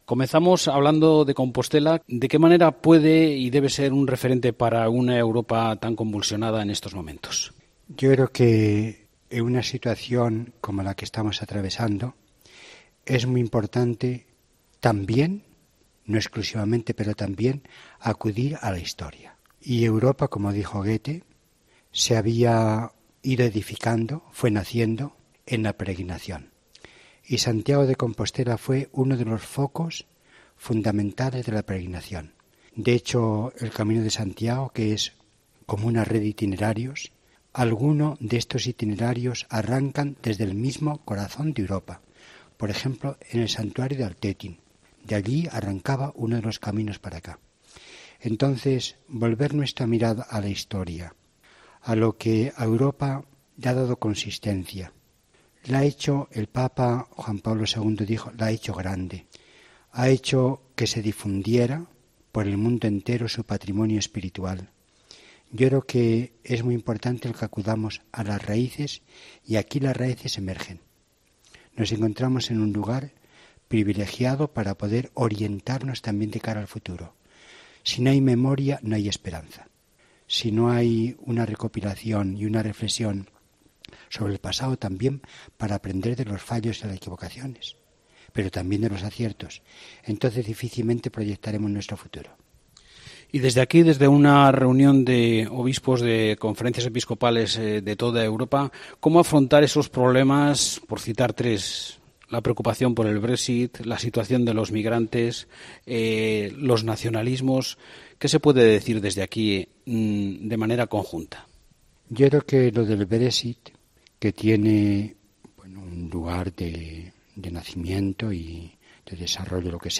El cardenal Blázquez ha atendido a la Cadena COPE durante la celebración de la Asamblea Plenaria del Consejo de Conferencias Episcopales de Europa (CCEE), que por primera vez acoge Santiago de Compostela, bajo el título Europa, ¿hora de despertar? Los signos de la esperanza.
En esta edición especial de 'La Linterna de la Iglesia', que se ha emitido desde la ciudad del apóstol,